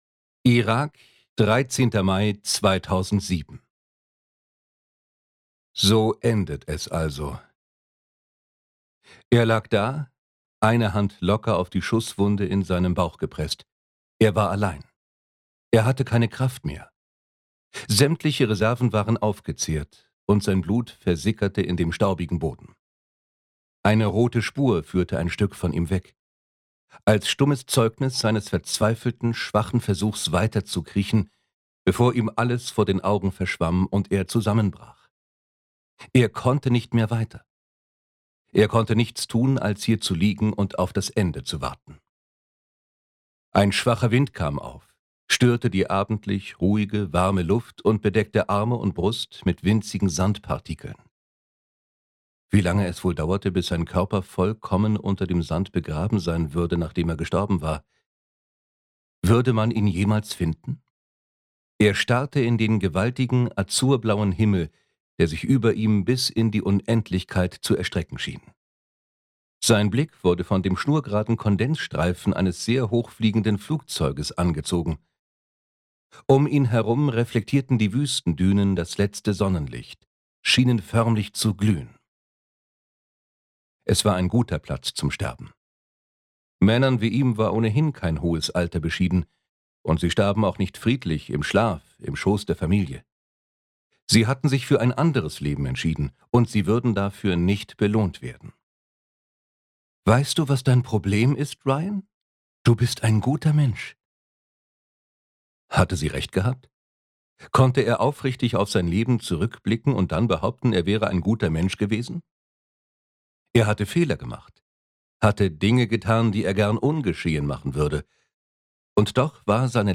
Hörbuch; Krimis/Thriller-Lesung